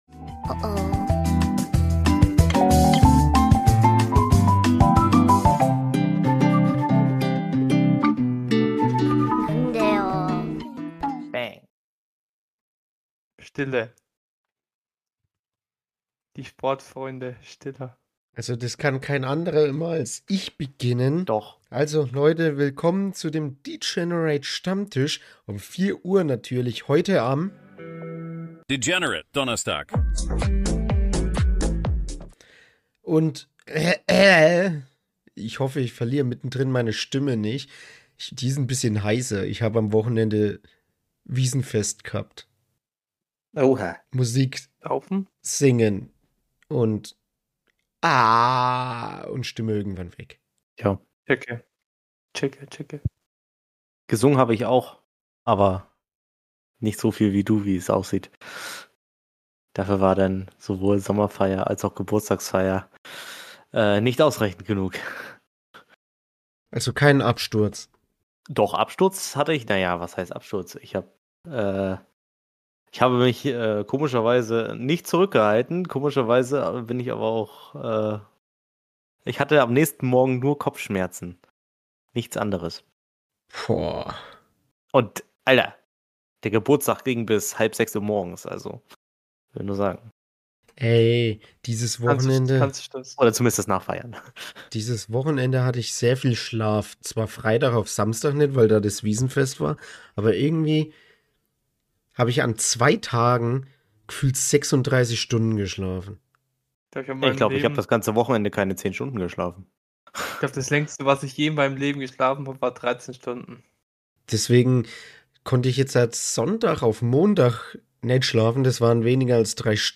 Deshalb gab es heute eine hitzige Diskussion darüber, ob Anime und generell dieses Spektrum schlecht für Kinder bzw. die Allgemeinheit ist, weil es zu sehr beeinflusst.